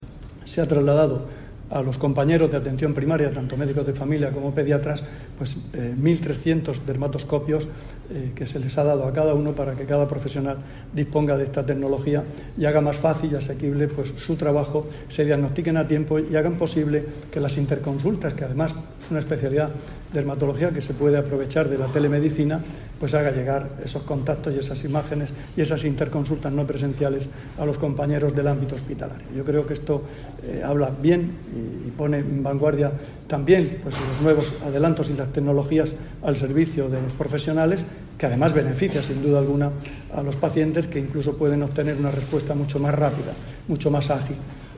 Declaraciones del consejero de Salud, Juan José Pedreño, en la inauguración de las Jornadas de dermatología pediátrica.